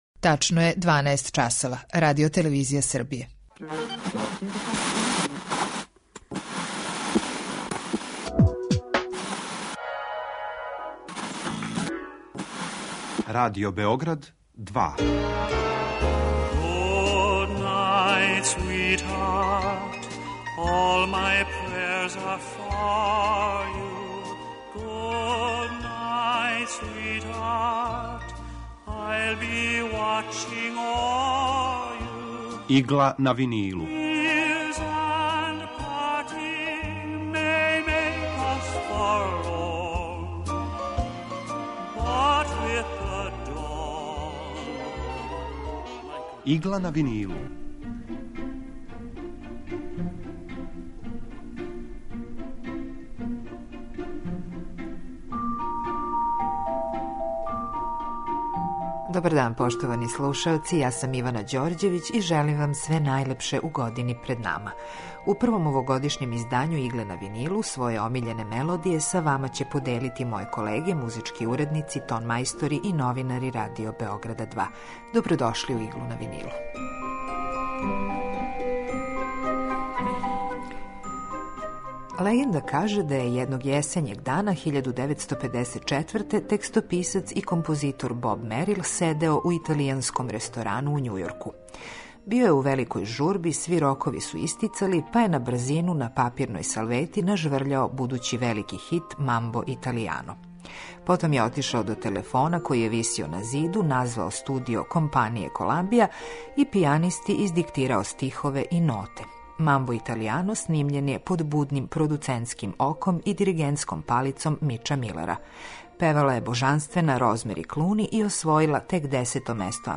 Најдраже евергрин мелодије